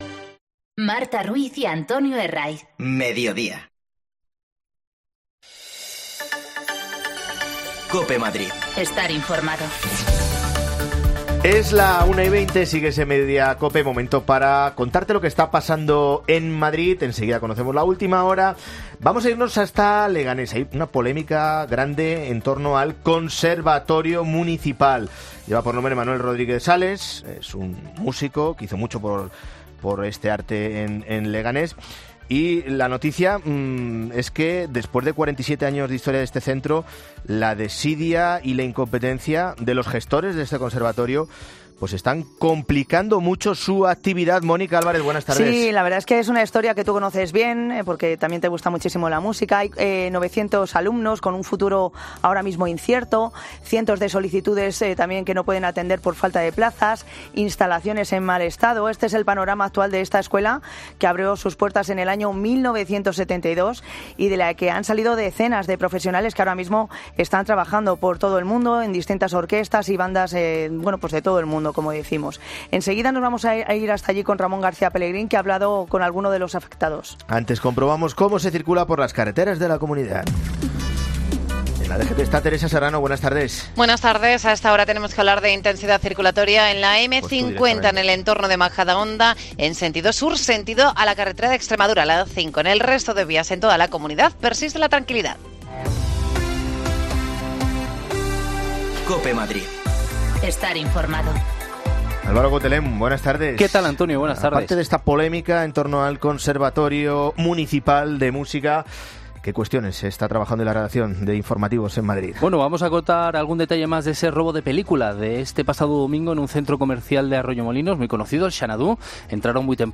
AUDIO: La escuela de Música de Leganés en estado casi de abandono por la incompentencia de los poíticos. Hablamos con alumnos afectados